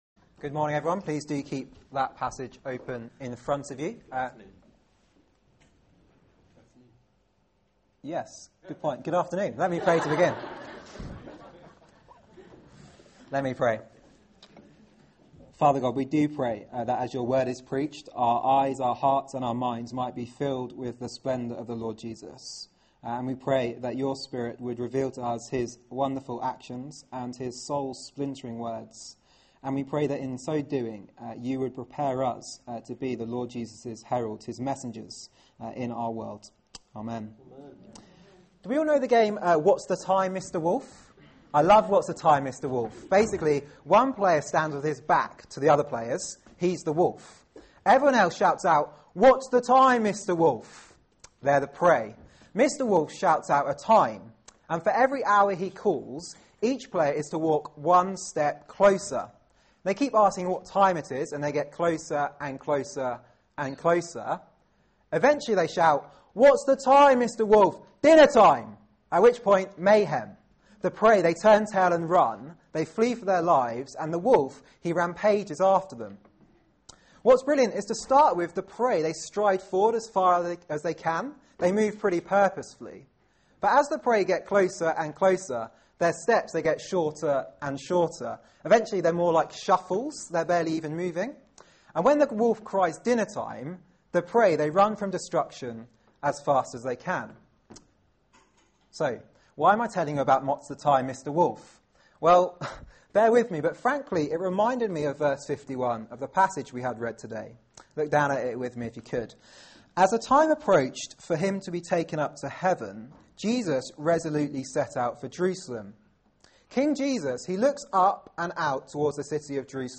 Media for 4pm Service on Sun 21st Oct 2012 16:00 Speaker
Sermon